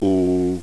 Vowels
All Pronunciation examples are taken from the online audio companion to Peter Ladefoged's "A Course in Phonetics" Textbook.
Vowel Pronunciation Example Translation